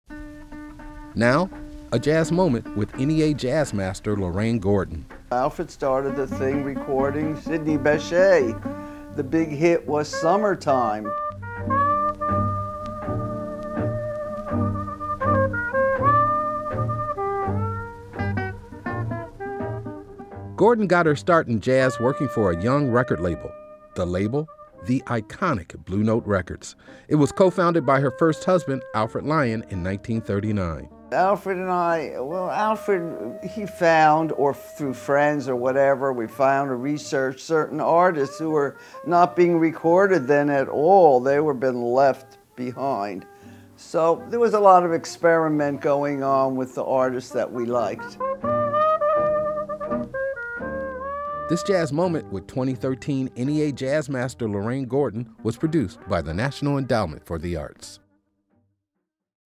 MUSIC CREDIT: Excerpt of "Summertime" written by George Gershwin, Ira Gershwin, and Dubose Heyward, and performed by Sidney Bechet, from the album, Best of Sidney Bechet, used courtesy of EMI Capitol and by permission of Warner Chappell Inc. (ASCAP)